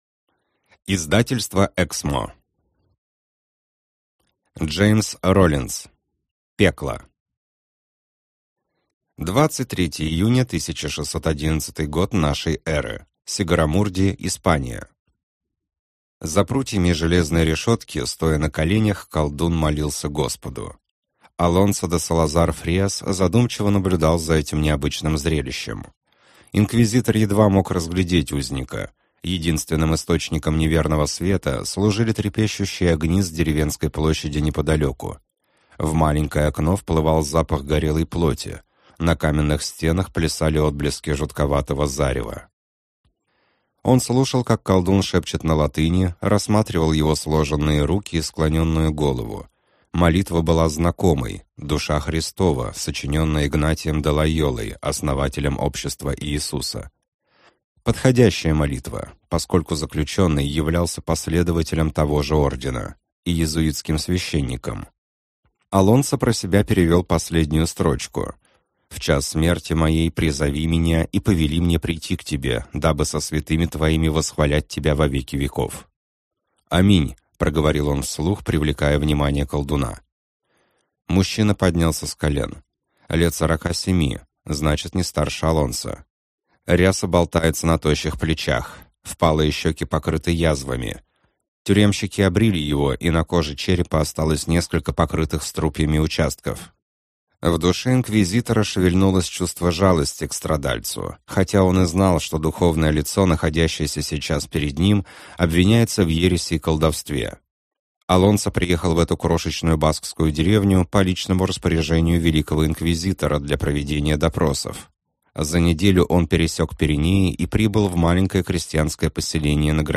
Аудиокнига Пекло - купить, скачать и слушать онлайн | КнигоПоиск